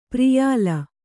♪ priyāla